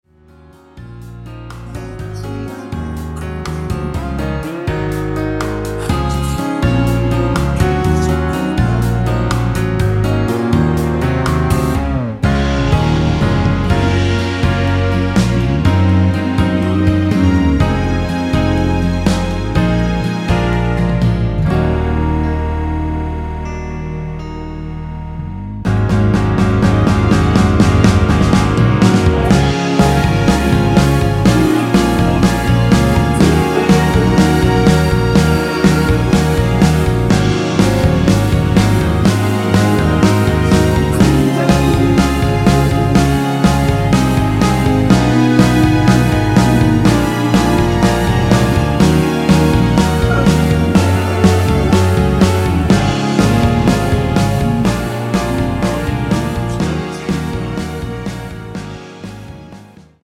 원키에서(-2)내린 코러스 포함된 MR 입니다.
Db
앞부분30초, 뒷부분30초씩 편집해서 올려 드리고 있습니다.
중간에 음이 끈어지고 다시 나오는 이유는